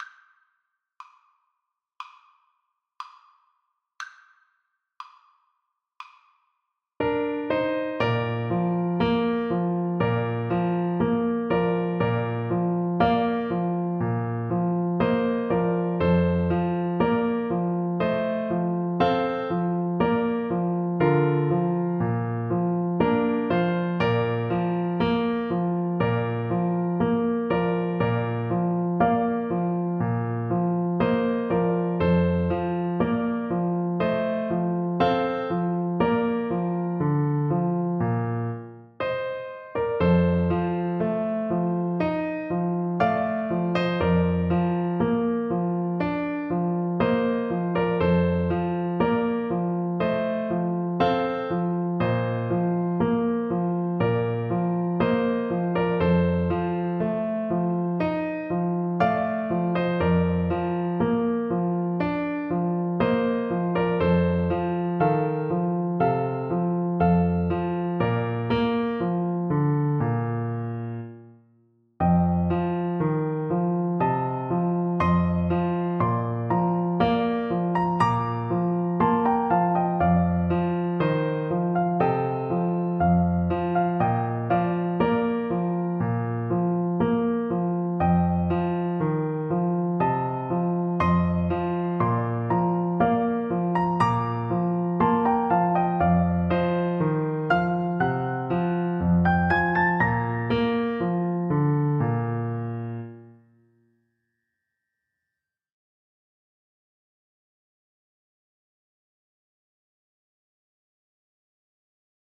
Clarinet version
Moderato
4/4 (View more 4/4 Music)
Classical (View more Classical Clarinet Music)